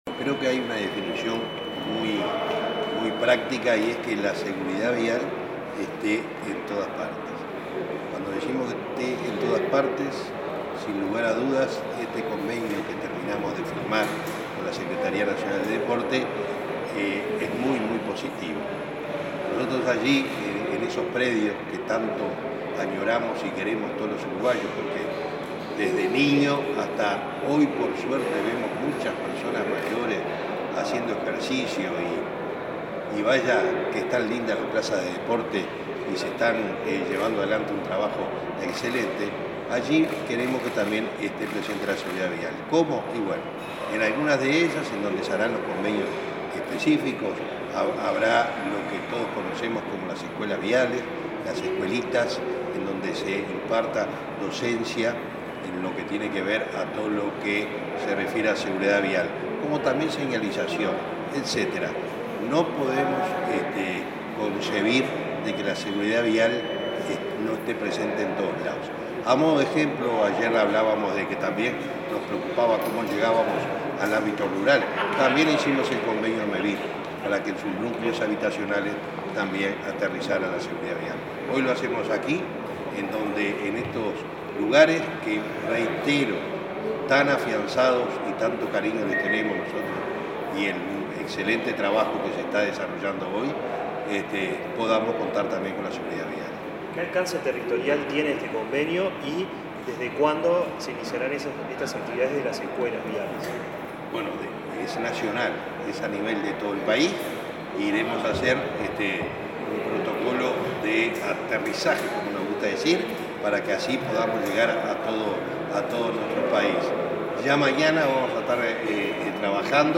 Entrevista al presidente de Unasev, Alejandro Draper
El presidente de Unasev, Alejandro Draper, dialogó con Comunicación Presidencial en Torre Ejecutiva, luego de firmar un acuerdo con el secretario